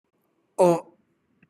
/o/